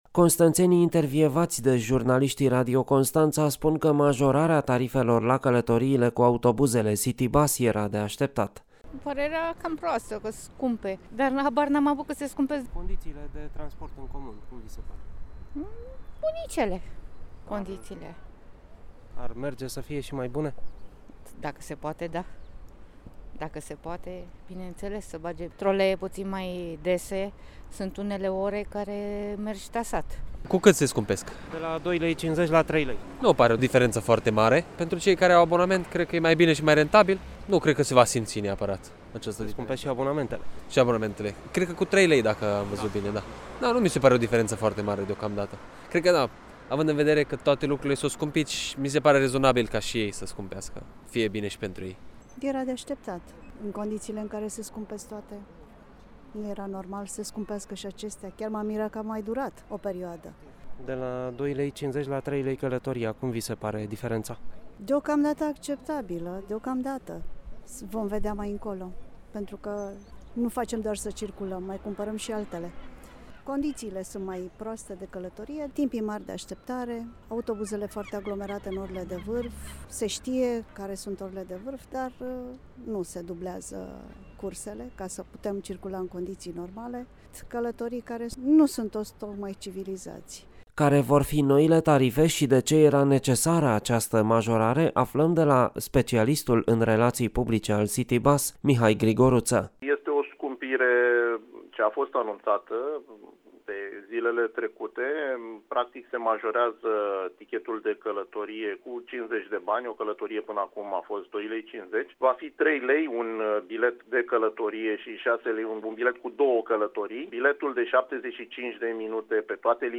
reportajul